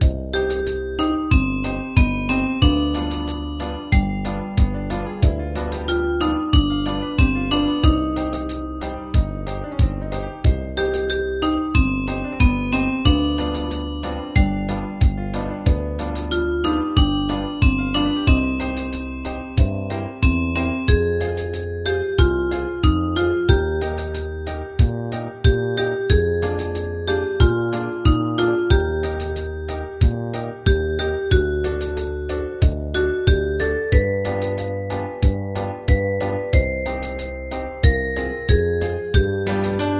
大正琴の「楽譜、練習用の音」データのセットをダウンロードで『すぐに』お届け！
カテゴリー: ユニゾン（一斉奏） .
歌謡曲・演歌